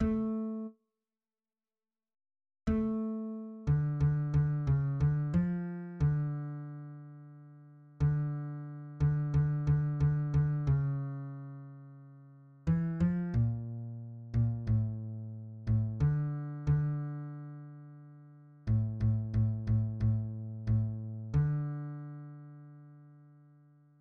<< \new Staff \with {midiInstrument = #"acoustic bass" instrumentName = "B" shortInstrumentName = "B"} << \set Staff.midiMinimumVolume = #14.7 \set Staff.midiMaximumVolume = #15.9 \set Score.currentBarNumber = #6 \bar "" \tempo 4=90 \time 4/4 \key f \major \clef bass \new Voice = "b1" \fixed c { \voiceOne a4 r2 r4 a4. d8 d d cis d f4 d2. d4. d8 d d d d cis2. e8 f bes,4. bes,8 a,4. a,8 d4 d2. a,8 a, a, a, a,4 a, d1 } >> \new Lyrics \lyricsto "b1" {\set fontSize = #-2 - VIL- NE, SHTOT FUN GAYST UN TMI- MES, VIL- NE, YI- DISH- LEKH FAR- TRAKHT VU ES MURM- LEN SHTI- LE TFI- LES, SHTI- LE SOY- DES FUN DER NAKHT } >> \midi{}